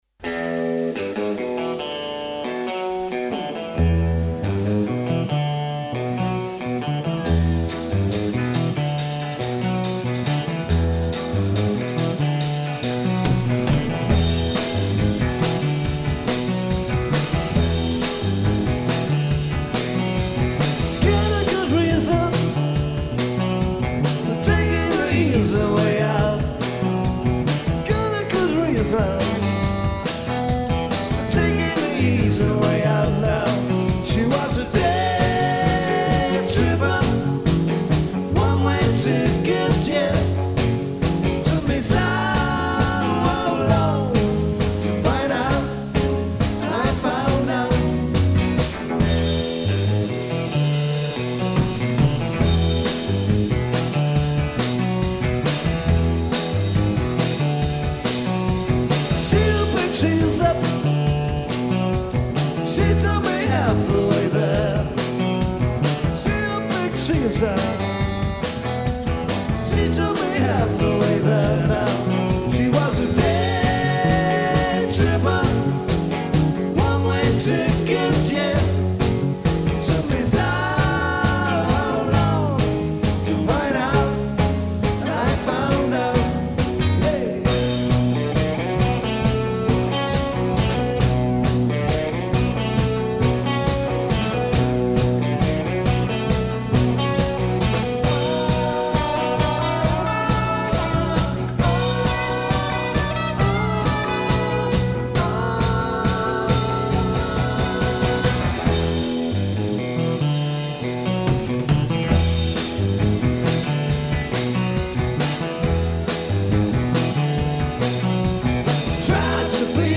lLive recording-